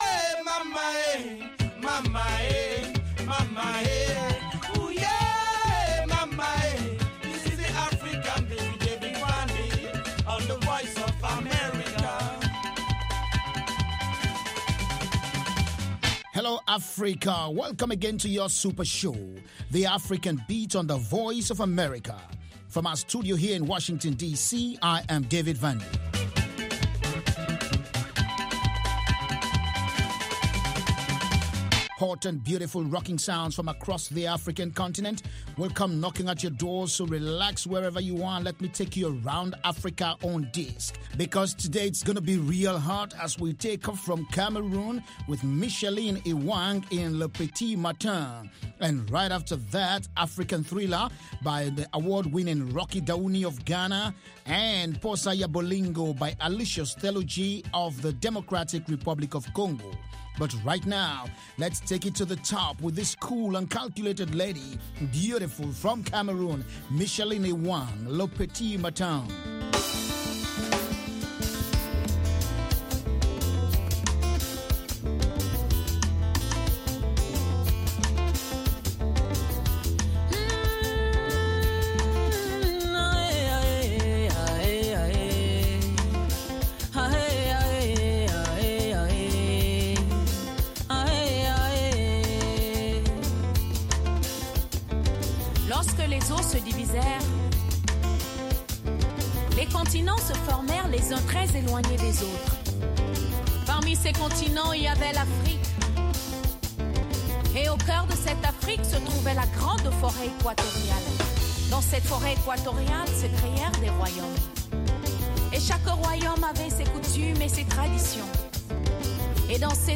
From Benga to Juju, Hip Life to Bongo Flava, Bubu to Soukous and more